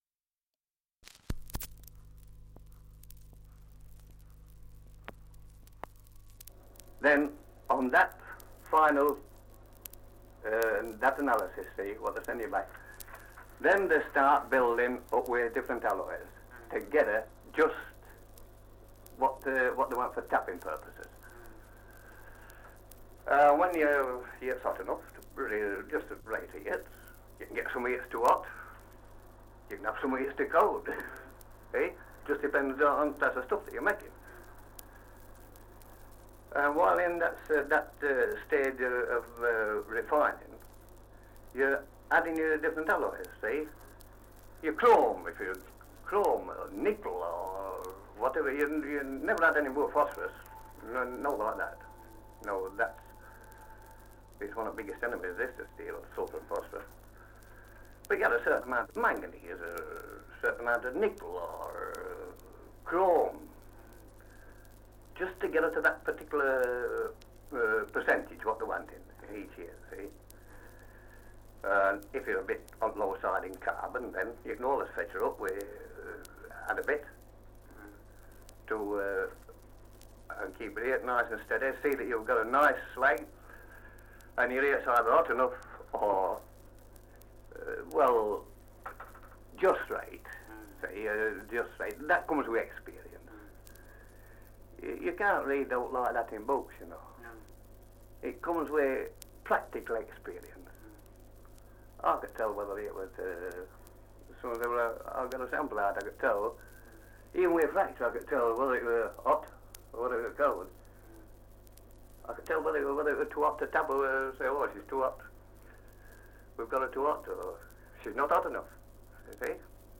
Survey of English Dialects recording in Sheffield, Yorkshire
78 r.p.m., cellulose nitrate on aluminium